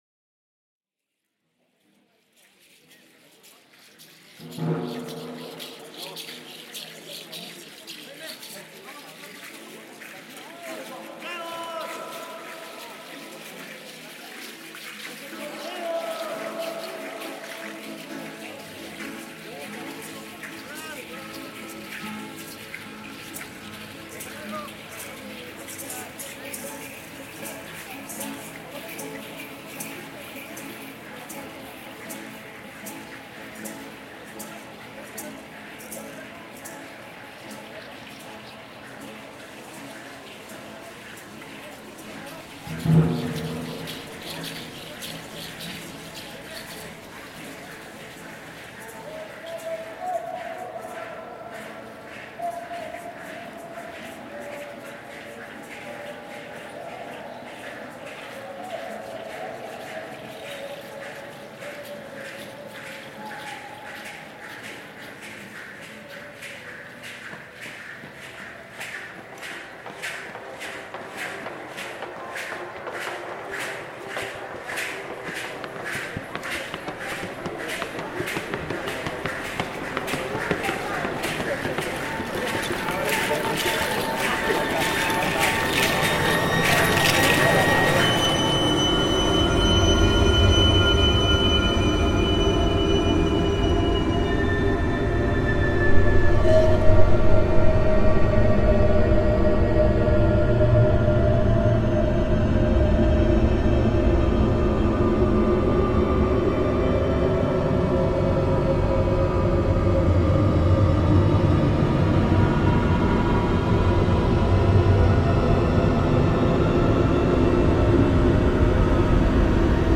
The subject of the recording itself is also a complex musical weave that entails histories of colonialization, indigenous cultures, and multiple lands. To add to this complexity, Spain being the location of this recording is a return to a distant origine.